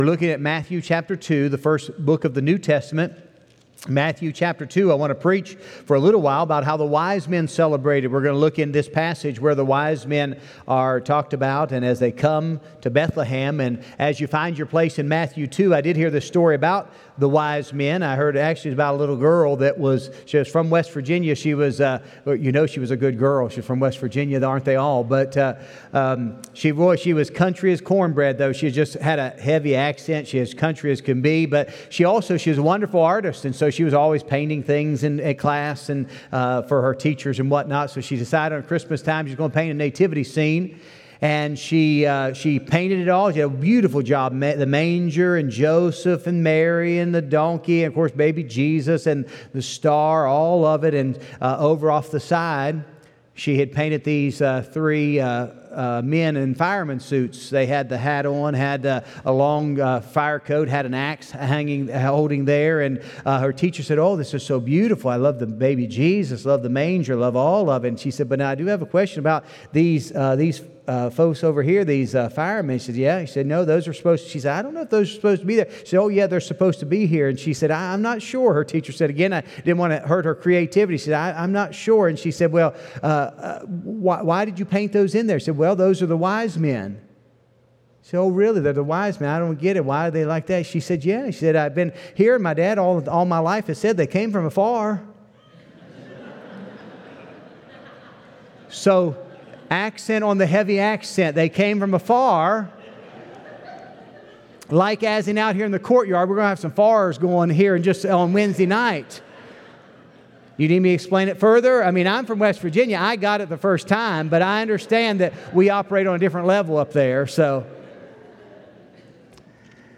Tennessee Thank you for joining us online for our services today.